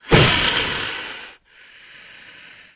c_wserpent_atk3.wav